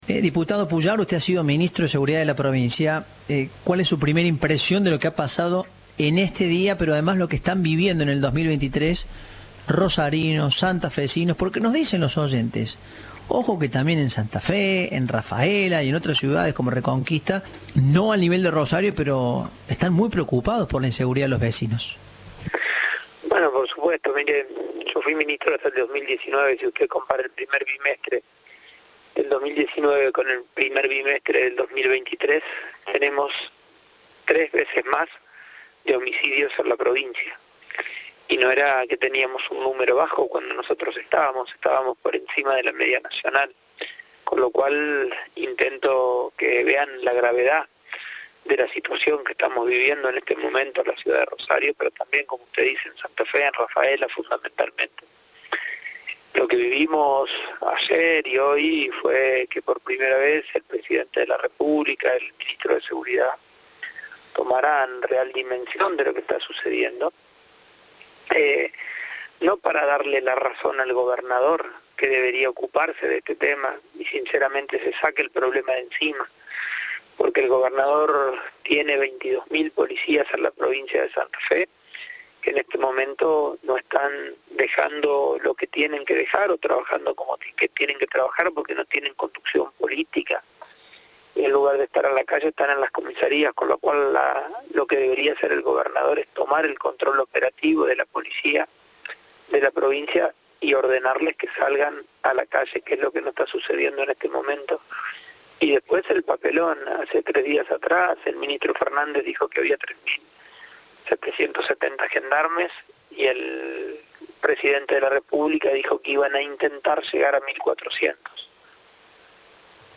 El diputado nacional y ex ministro de seguridad de la provincia de Santa Fe, Maximiliano Pullaro, en diálogo con Cadena 3 brindó conceptos sobre la inseguridad que vive la provincia de Santa Fe.
Entrevista de "Informados, al Regreso".